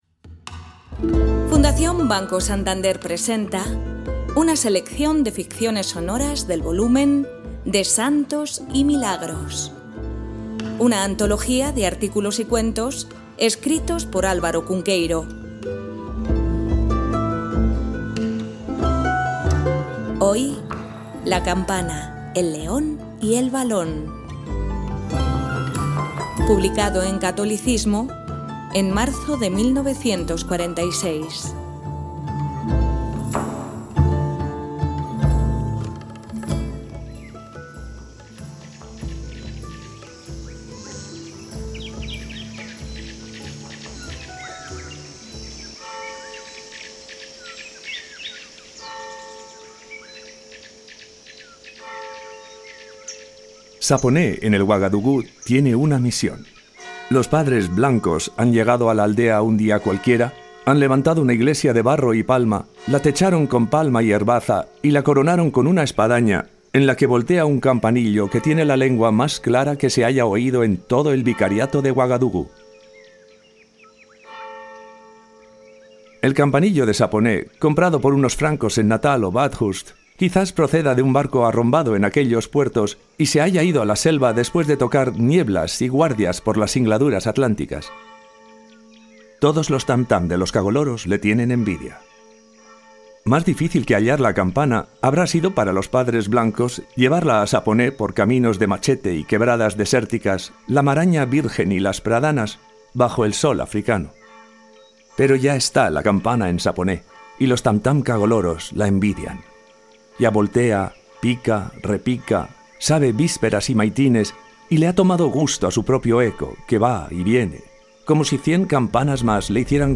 Ficciones sonoras
Kipling, la India, África, los leones y las campanas figuran en este relato mágico donde los haya, donde los efectos sonoros nos sumergirán y elevarán a otros mundos posibles.